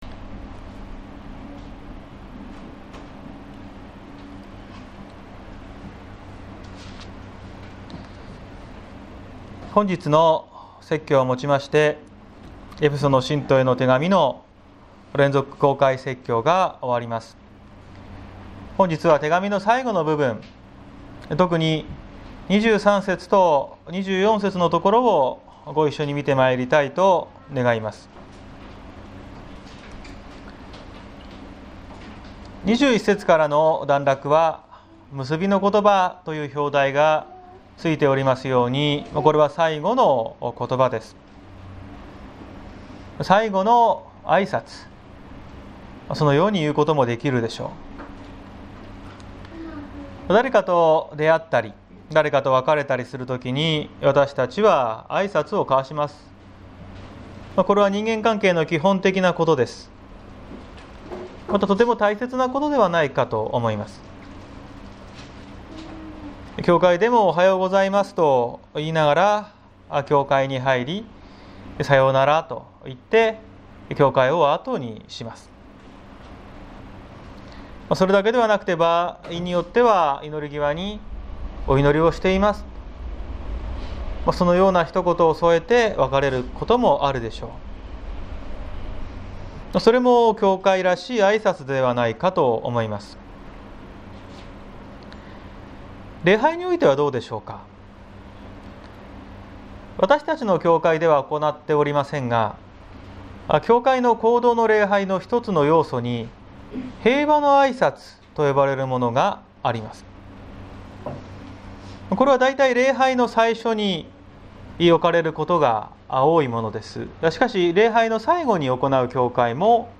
2021年05月30日朝の礼拝「変わらぬ愛」綱島教会
綱島教会。説教アーカイブ。